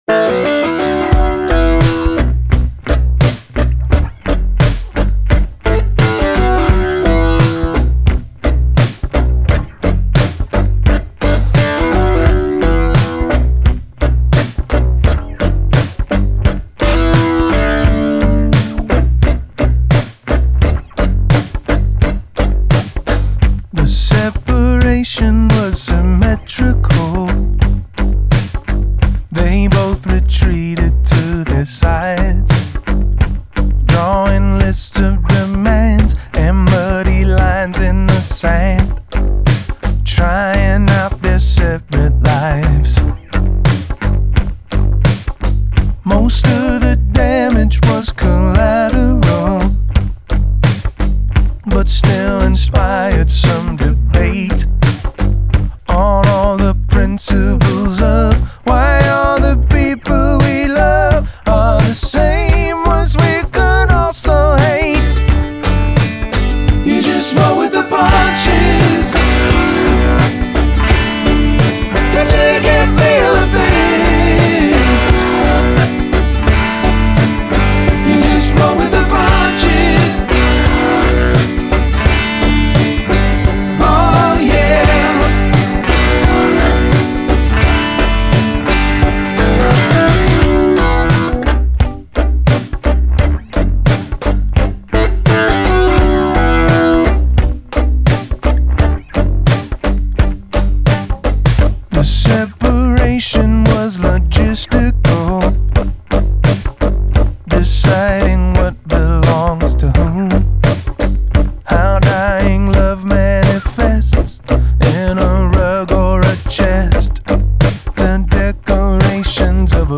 definitely alt-country/folk sound